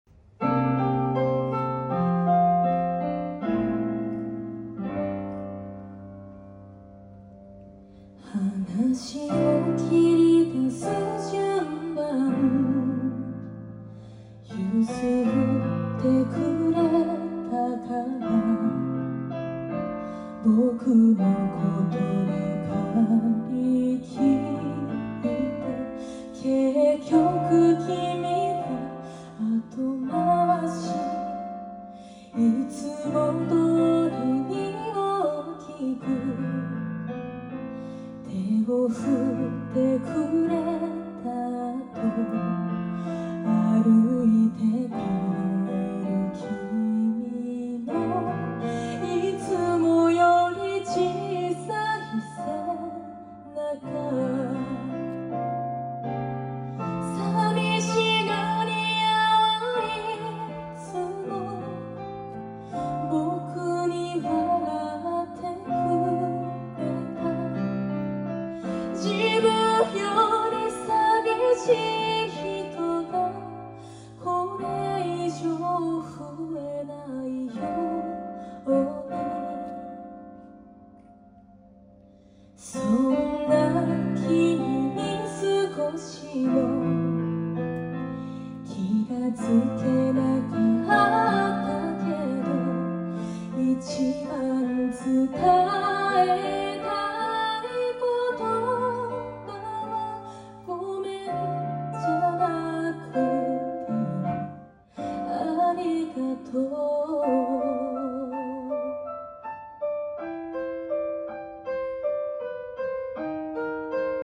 2025.8.9(土)横浜ミントホール sono pazza di te